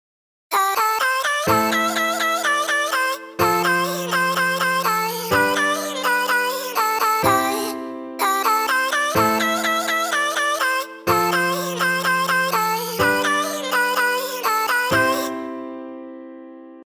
今回は例として、ちょっと切ない感じのEDMにしてみます。
つまり、ちょっと切ないコード進行・4つ打ちドラム・シンセでいきます。
以前の記事で解説した要素を考えながら、各音の高さを変えます。